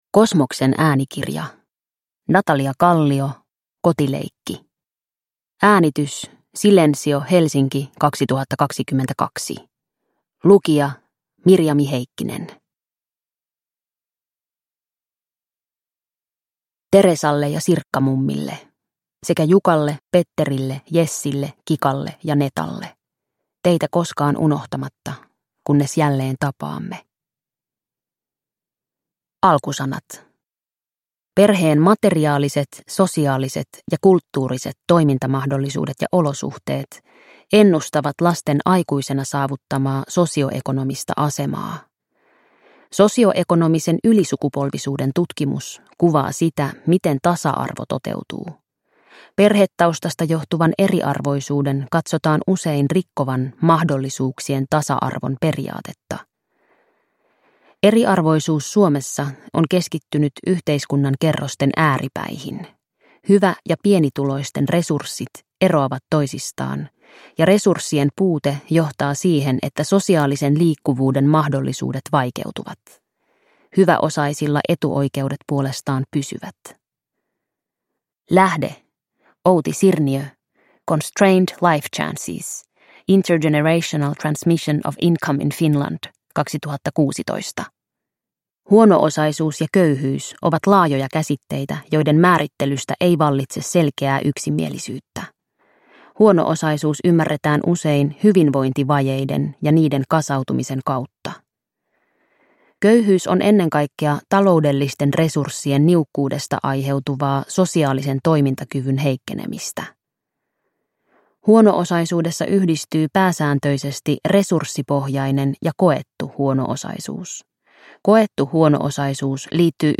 Kotileikki – Ljudbok – Laddas ner